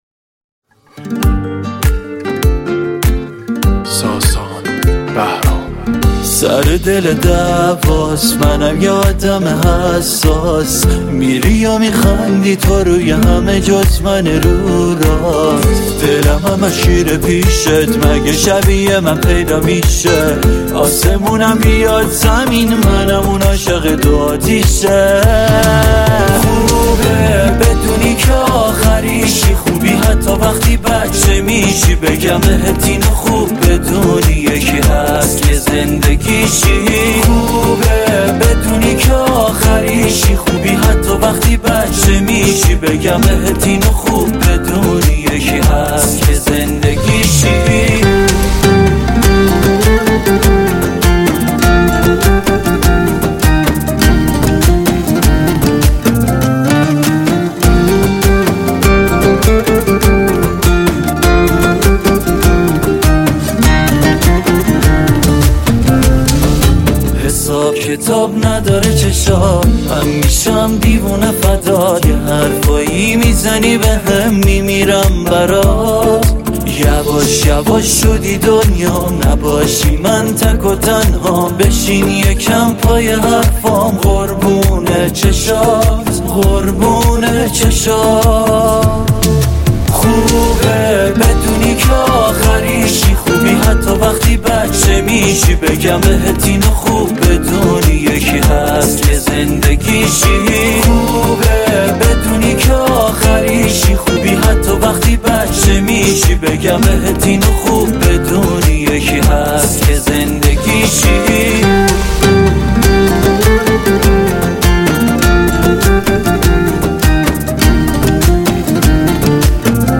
آهنگ ایرانی رقص عروس داماد